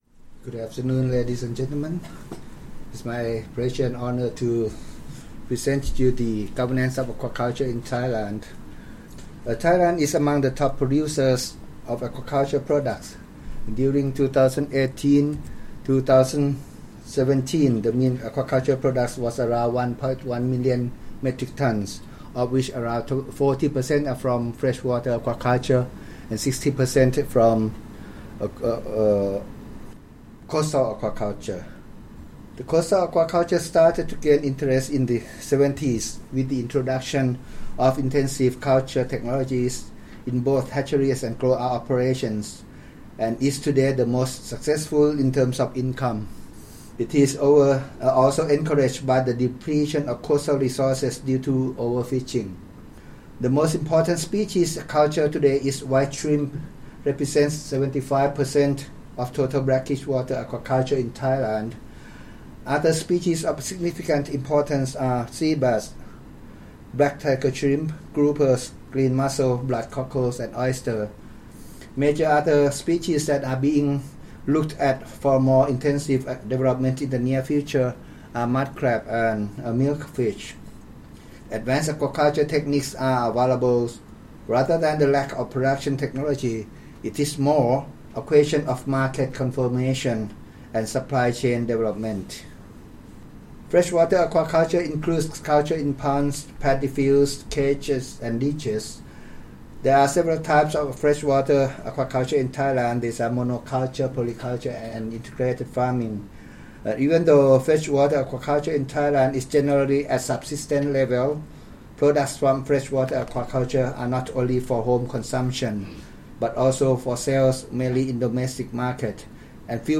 Audio recording of presentation delivered at the Consultation on Strengthening Governance of Aquaculture for Sustainable Development in Asia-Pacific, 5-6 November 2019, Bangkok, Thailand.